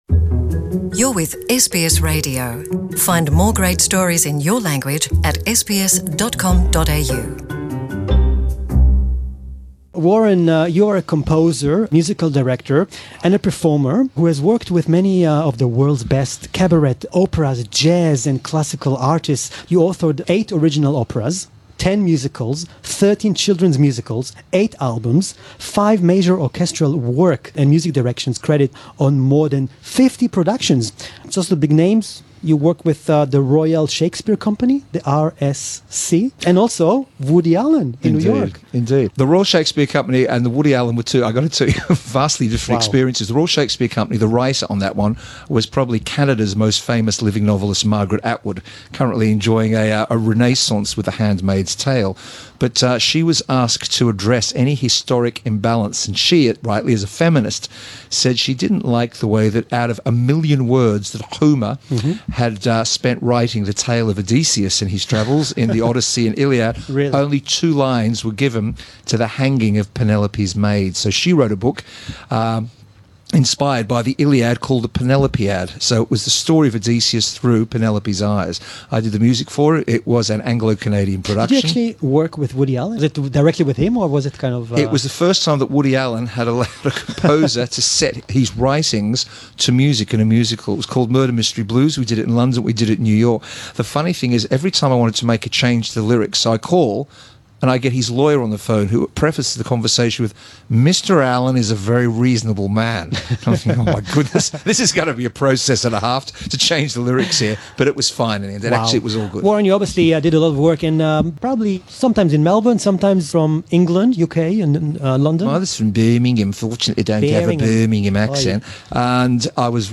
chatting about their latest musical theater production